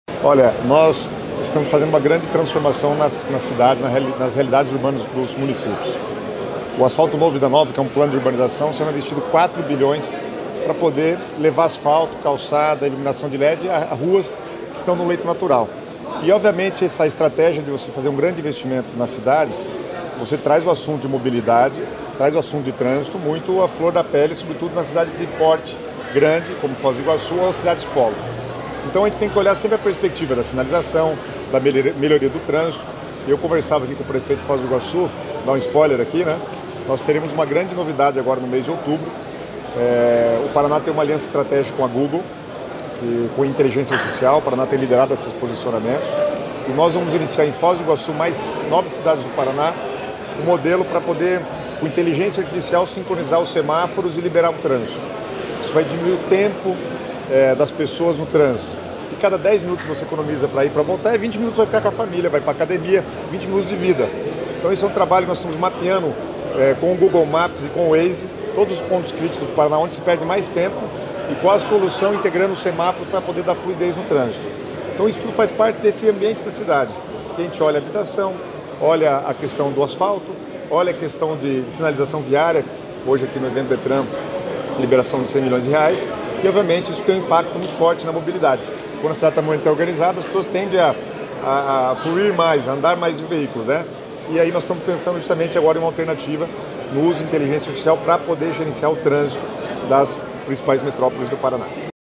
Sonora do secretário das Cidades, Guto Silva, sobre a liberação de R$ 100 milhões para sinalização viária das cidades